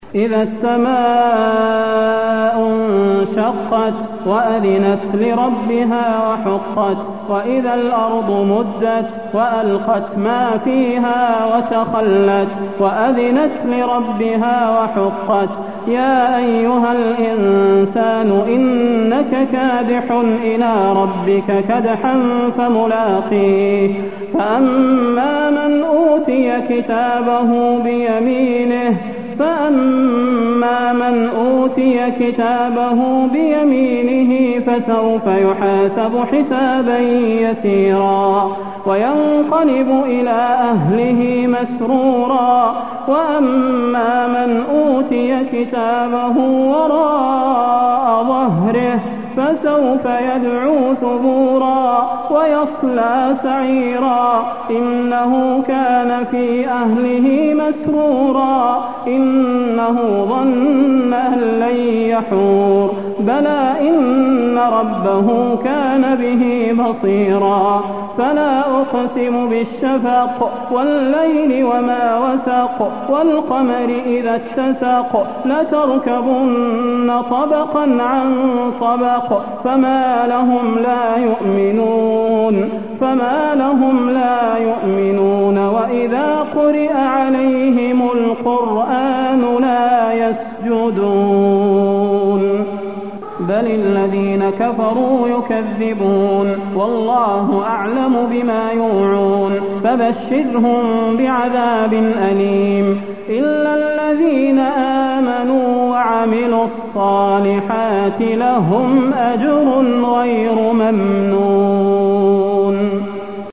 المكان: المسجد النبوي الانشقاق